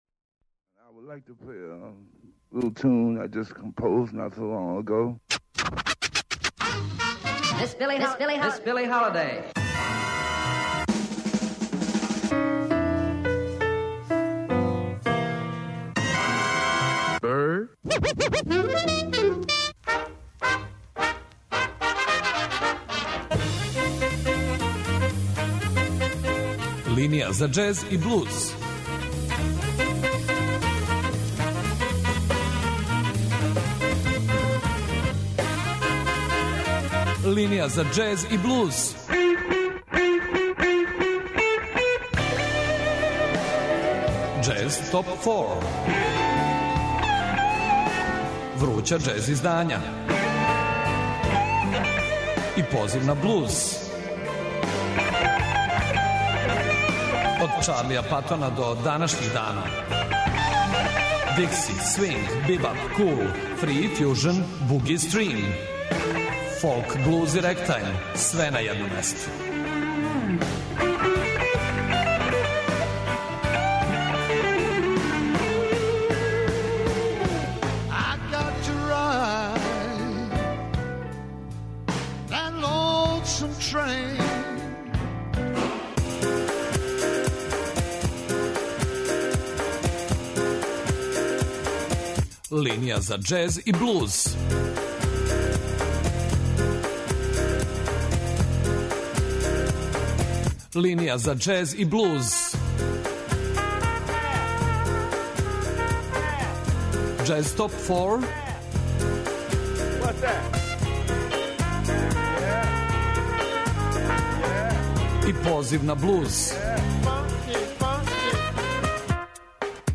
хрватског вибрафонисте
блуз пијанисткињу